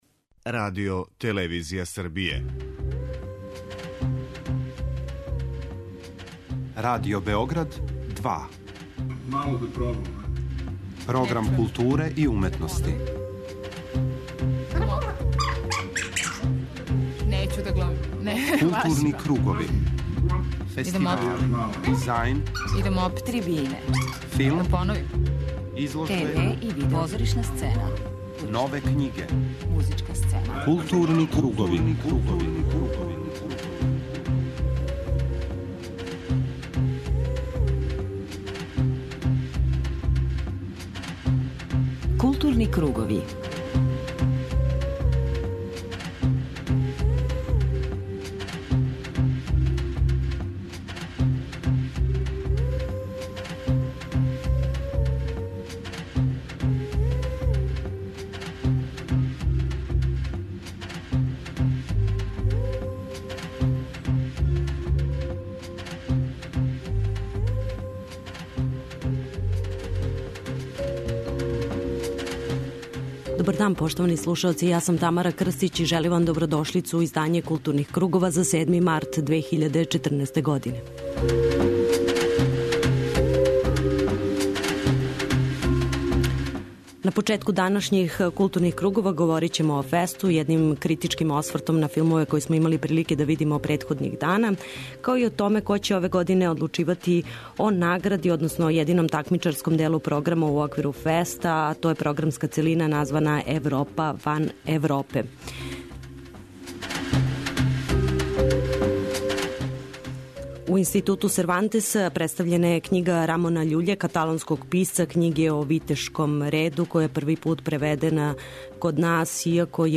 преузми : 53.98 MB Културни кругови Autor: Група аутора Централна културно-уметничка емисија Радио Београда 2.